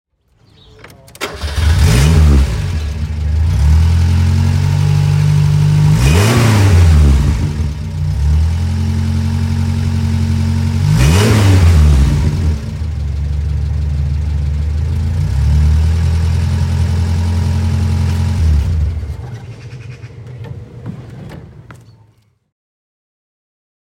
Renault Rodéo 4 (1980) - Starten und Leerlauf
Renault_Rodeo_4_1980.mp3